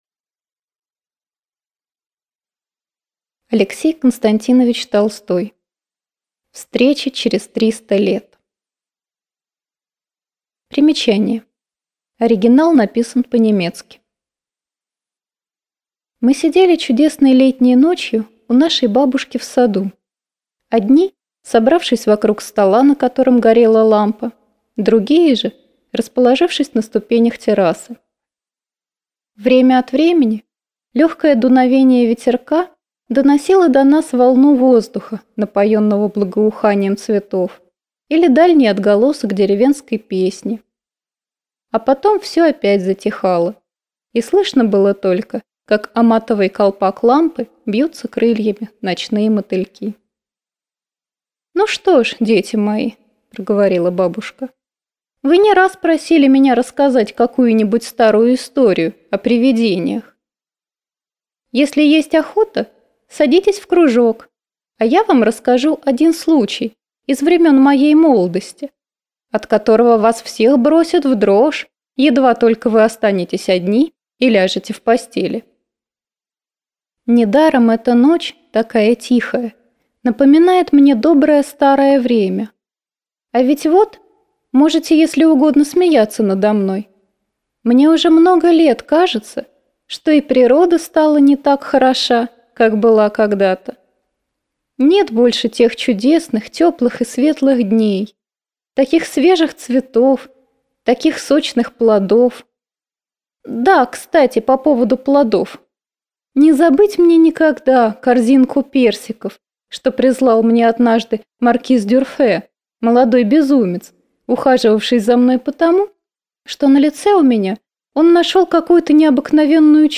Аудиокнига Встреча через 300 лет | Библиотека аудиокниг